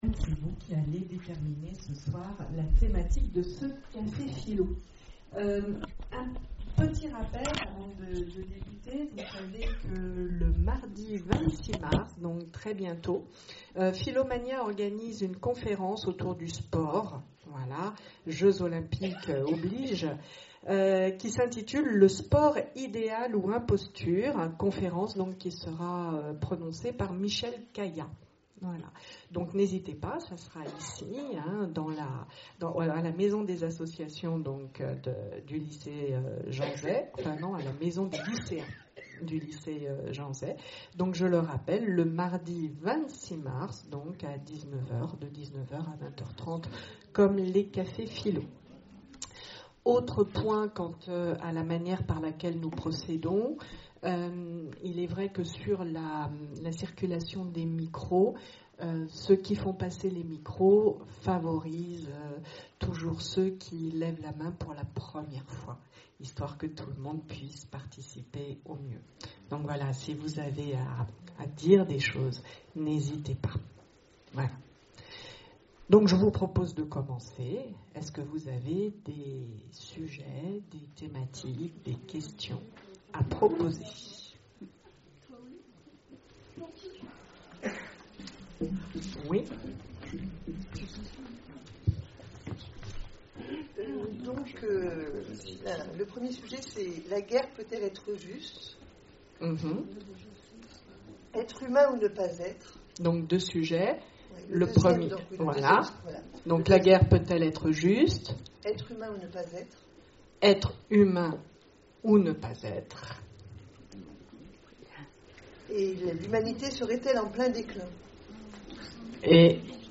Conférences et cafés-philo, Orléans
CAFÉ-PHILO PHILOMANIA La guerre peut-elle être juste ?